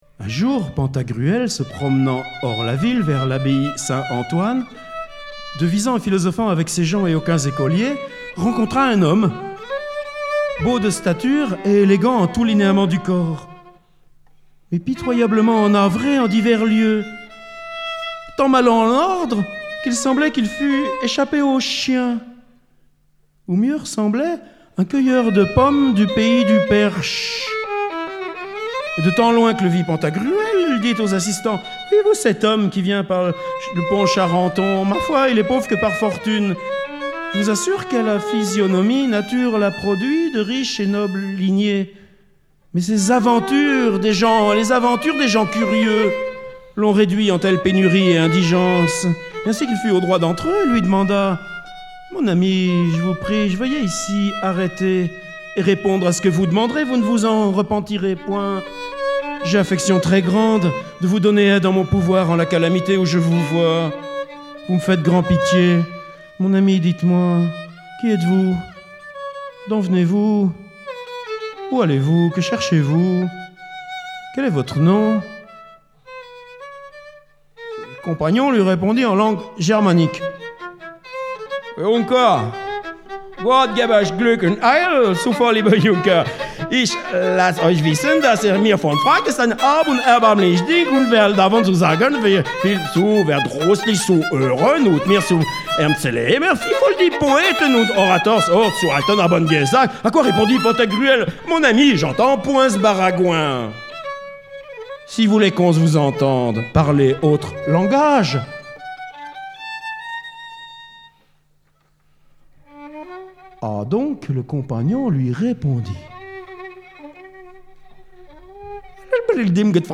Contrairement aux pages ultérieures, ce sont juste des lectures voix nue.